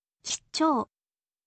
kitchou